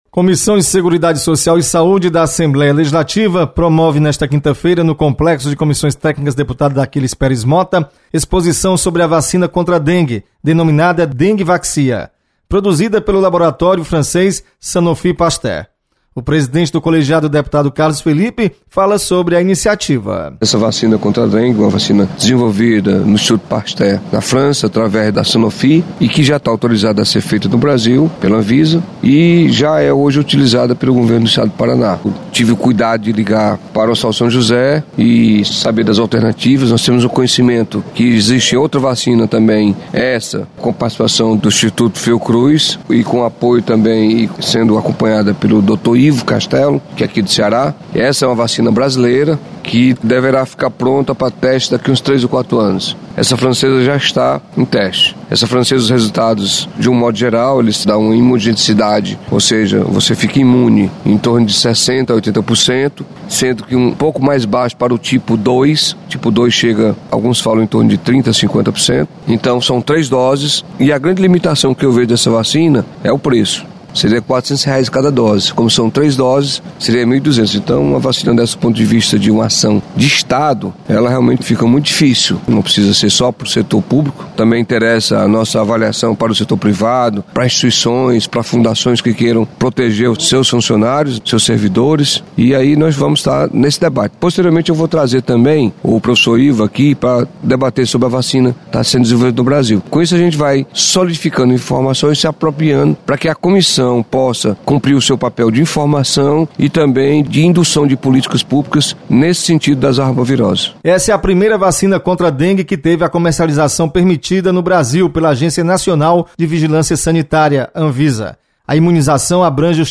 Comissão de Seguridade Social e Saúde debate sobre vacina contra a dengue. Repórter